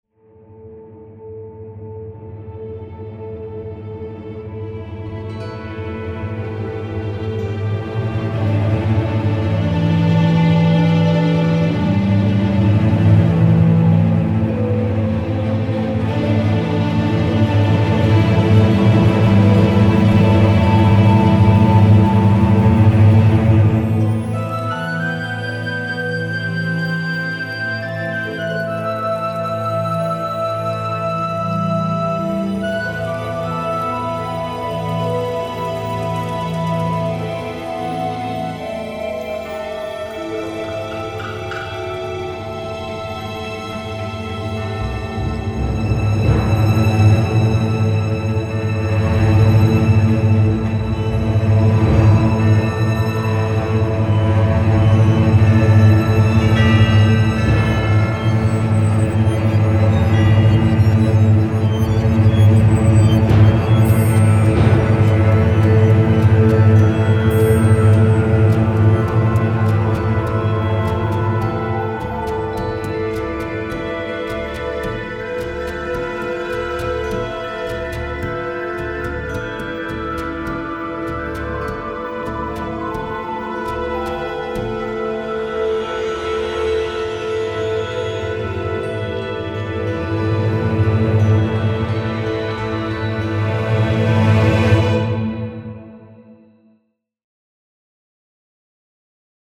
幻想的・神秘的な情景を表した音楽素材です。
夜の怖さ。その奥にある美しさ。